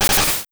VineWhip_Start.wav